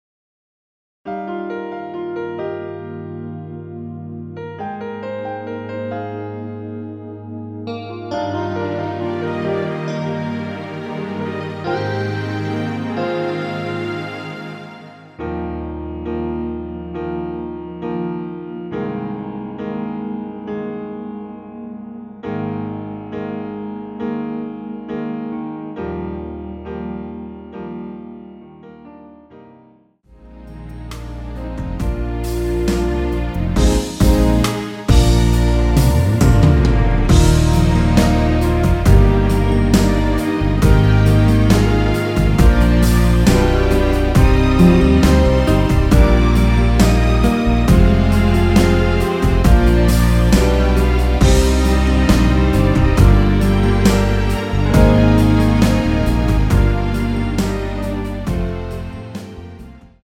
Eb
◈ 곡명 옆 (-1)은 반음 내림, (+1)은 반음 올림 입니다.
앞부분30초, 뒷부분30초씩 편집해서 올려 드리고 있습니다.
중간에 음이 끈어지고 다시 나오는 이유는